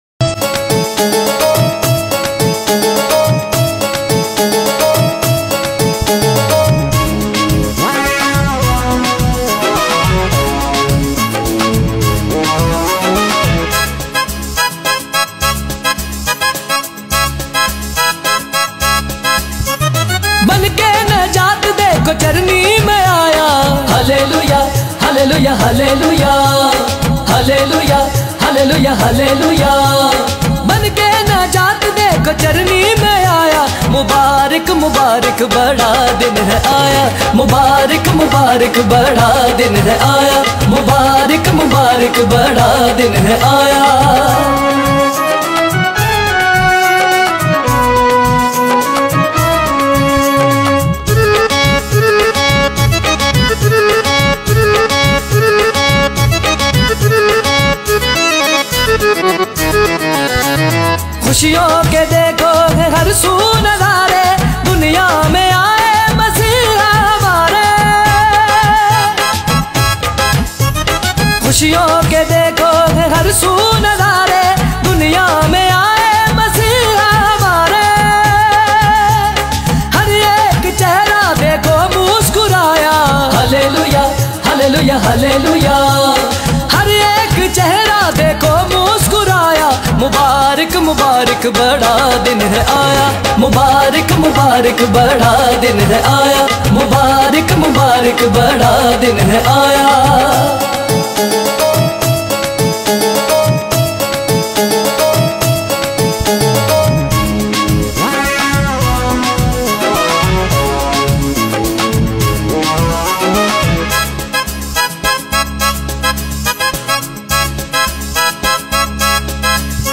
Listen and download Gospel songs